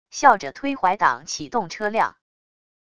笑着推怀挡启动车辆wav音频生成系统WAV Audio Player